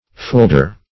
Search Result for " foulder" : The Collaborative International Dictionary of English v.0.48: Foulder \Foul"der\, v. i. [OE. fouldre lightning, fr. F. foudre, OF. also fouldre, fr. L. fulgur.